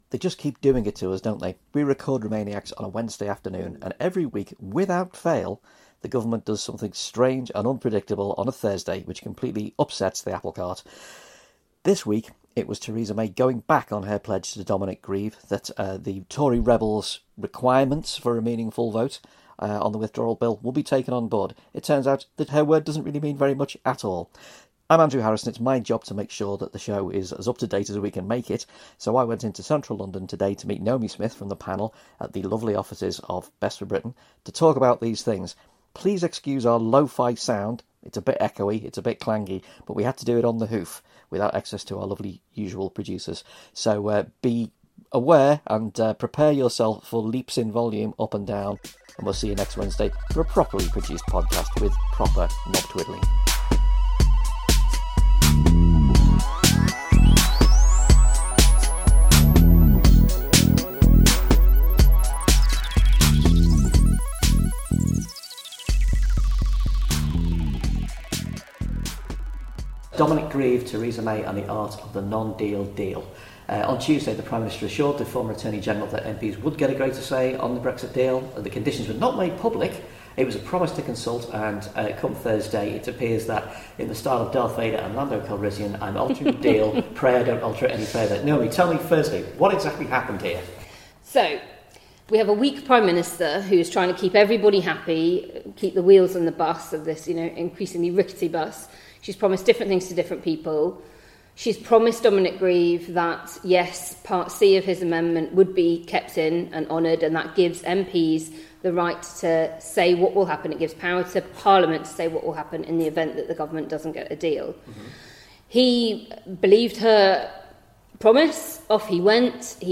So YET AGAIN we've recorded a quick-and-dirty response to events.
This time we’re not recording in a pub or a park but in the echoey chambers of Best For Britain, so beware – sound quality is rough and ready and volume levels are unpredictable.